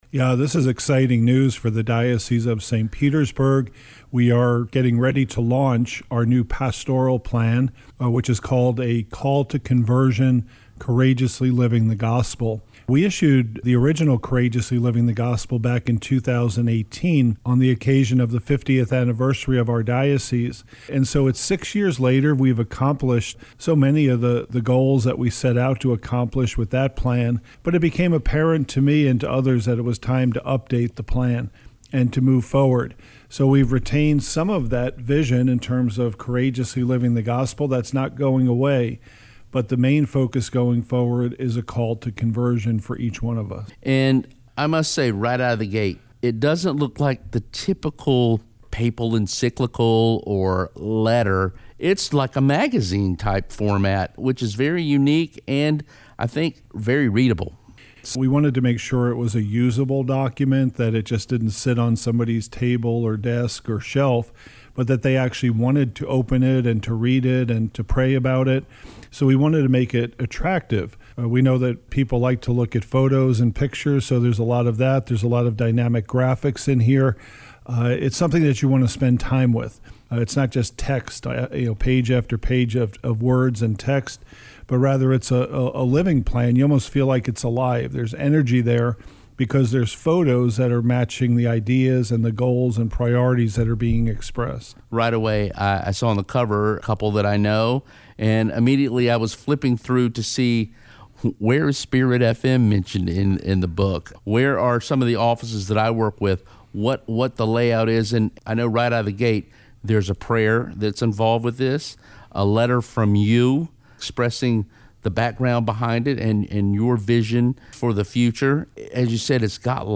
Bishop-Parkes-clip-summarizing-A-Call-to-Conversion.mp3